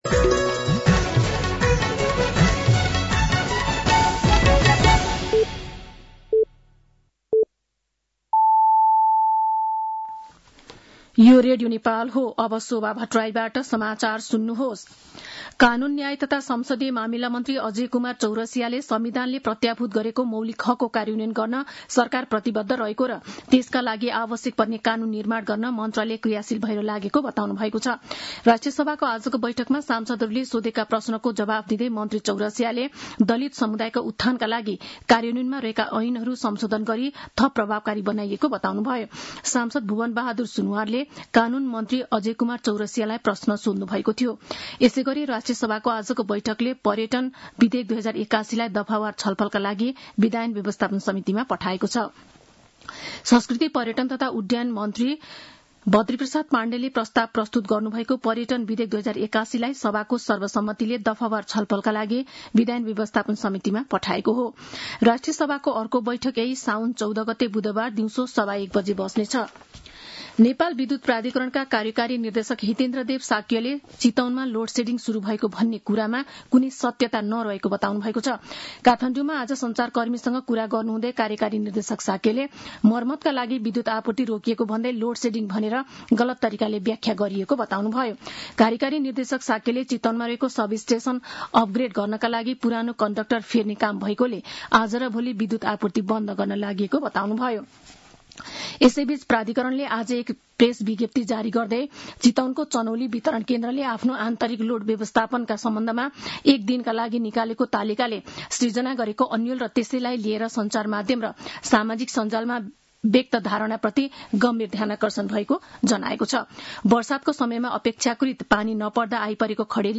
साँझ ५ बजेको नेपाली समाचार : ११ साउन , २०८२